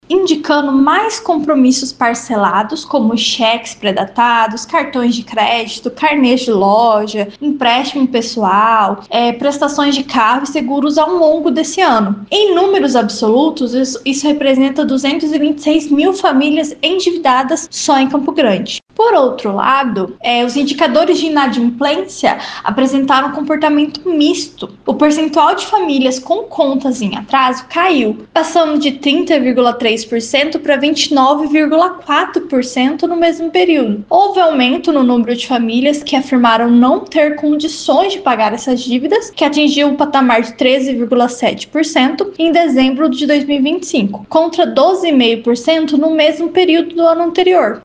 Em entrevista ao Agora 104 a economista deu mais informações.